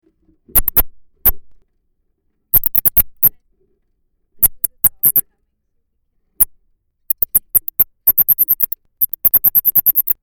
Netopýr rezavý
Nyctalus noctula
Záznam echolokace v systému heterodyning
Je to velmi společenský druh a vydává širokou škálu zvuků. Hlasité echolokační signály jsou poměrně nízké, často můžeme slyšet i jejich koncovou fázi při lovu. Charakteristické pro jeho echolokaci je střídání dlouhých nižších a vyšších signálů s největší hlasitostí na frekvenci 22–26 kHz.